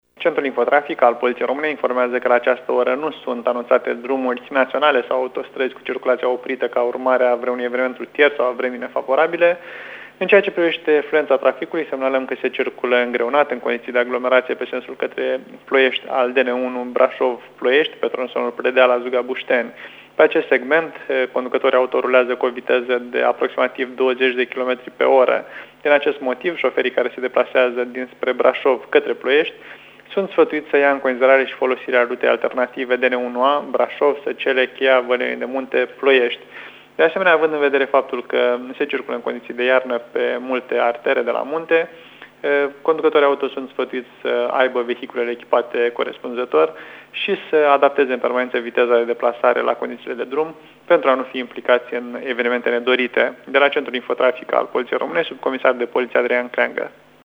subcomisarul de poliție